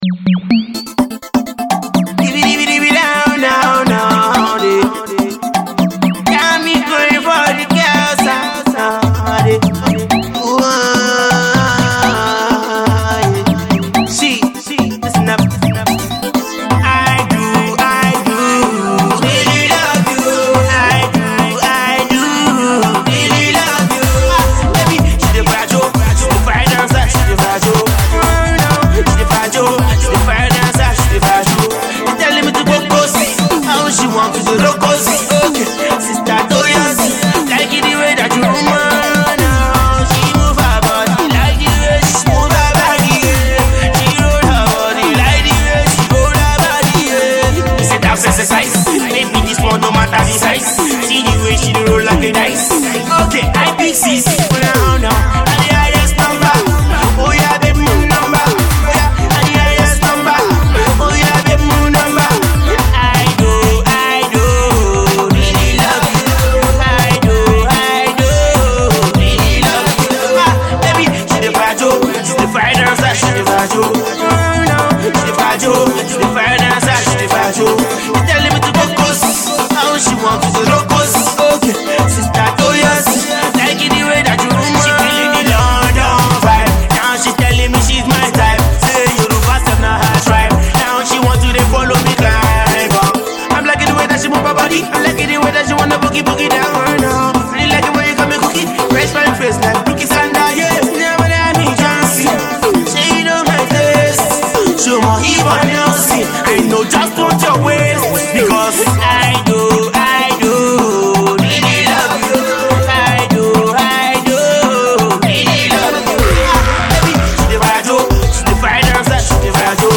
The modern day Fuji crooner
Afro Pop